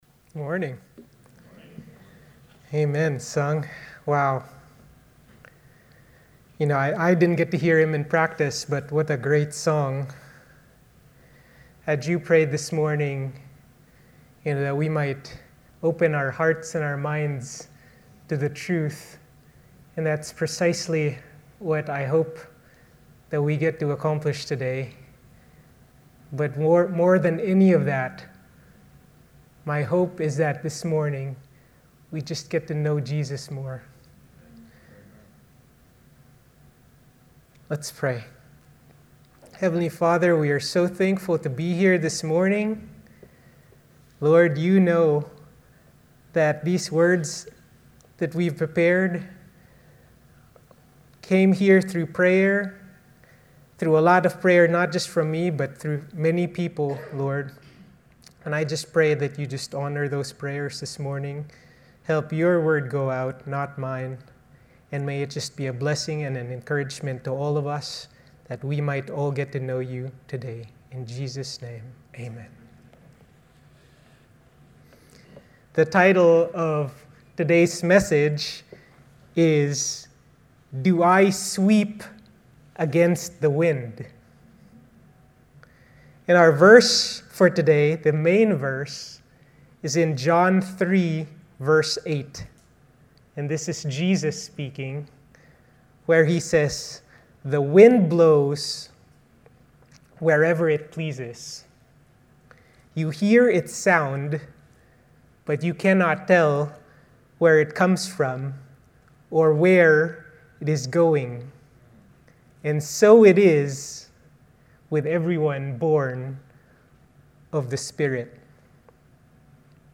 Sermon 04/26/2026 Do I Sweep Against The Wind?